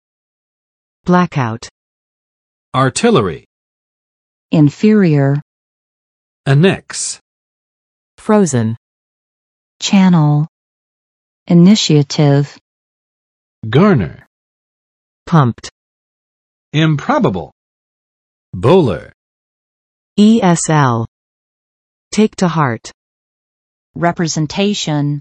[ˋblæk͵aʊt] n. 停电